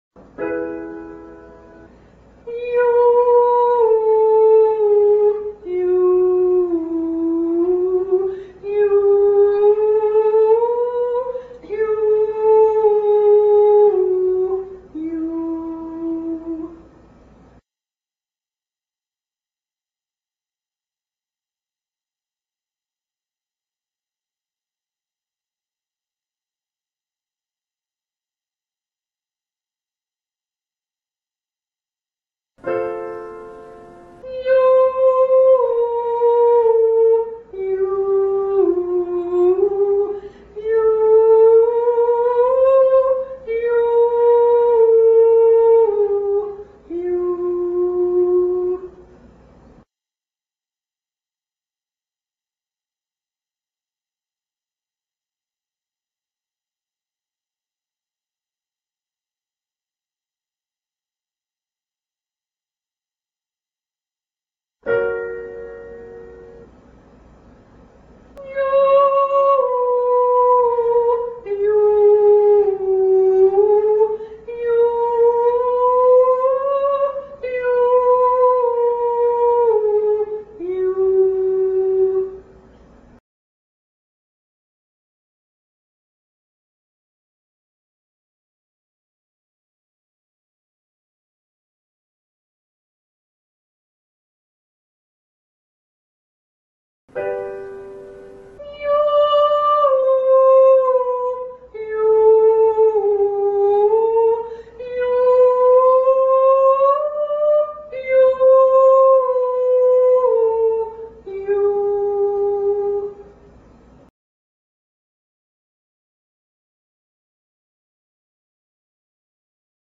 1107 Breathe Every Three Yoo Ascending
Vft-1107-Breathe-Every-Three-Yoo-Ascending.mp3